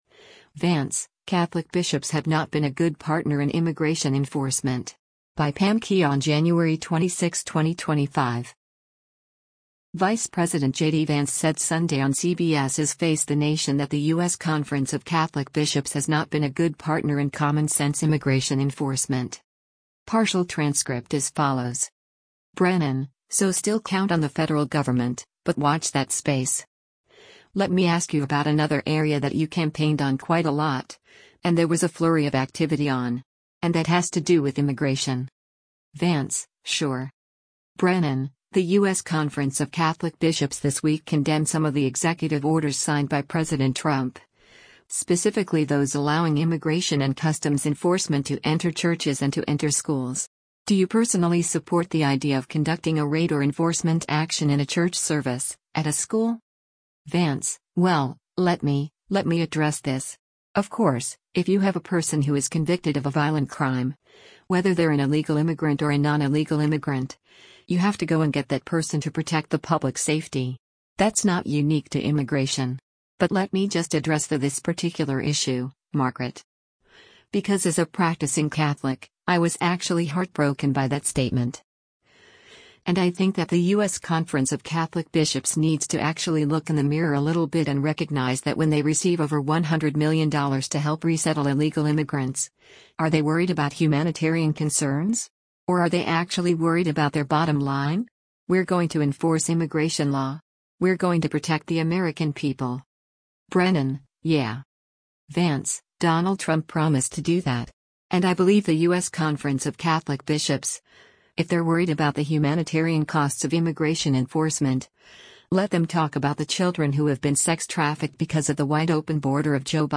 Vice President JD Vance said Sunday on CBS’s “Face the Nation” that the U.S. Conference of Catholic Bishops has “not been a good partner in common sense immigration enforcement.”